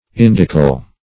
Indical \In"dic*al\, a.